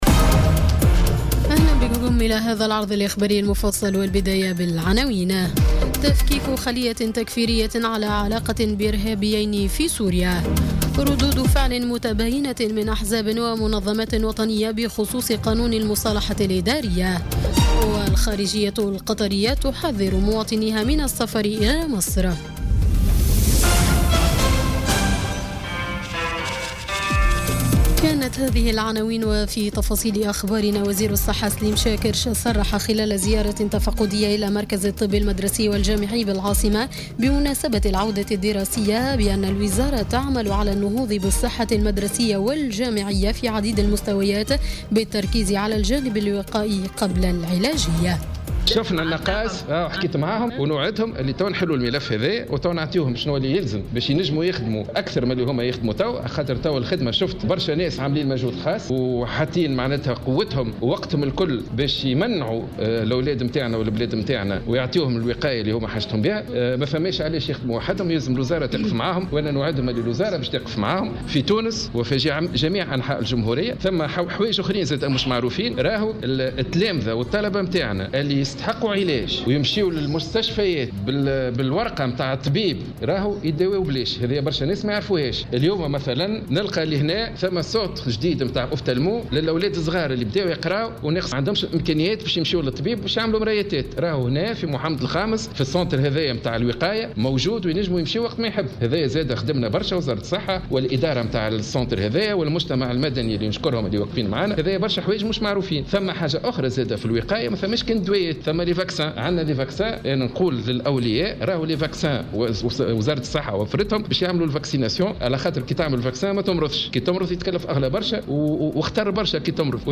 نشرة أخبار السابعة مساء ليوم الجمعة 15 سبتمبر 2017